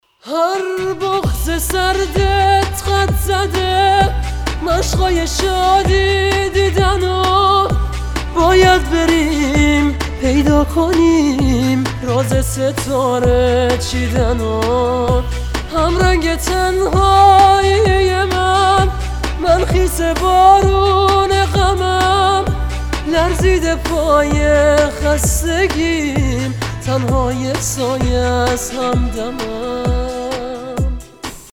رینگتون زیبا و رمانتیک